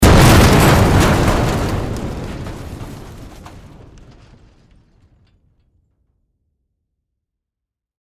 Звуки взрыва танка
Звук уничтожения танка